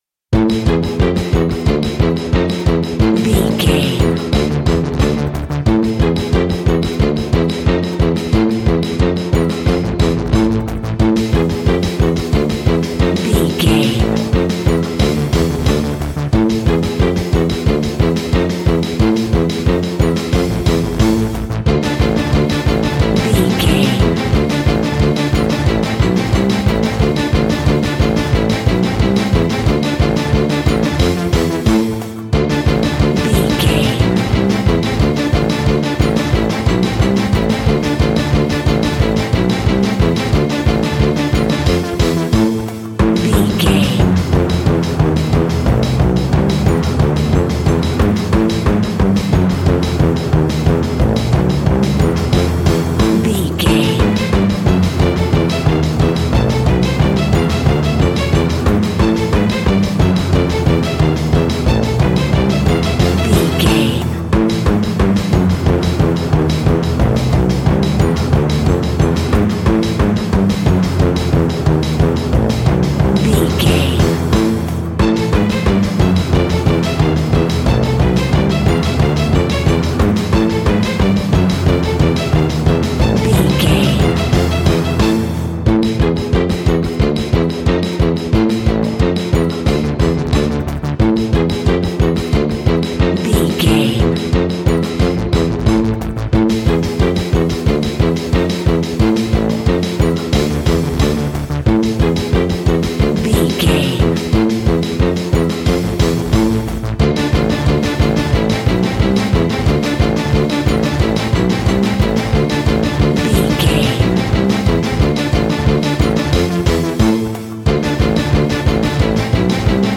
royalty free music
Aeolian/Minor
scary
ominous
suspense
eerie
strings
brass
synthesiser
percussion
piano
spooky
horror music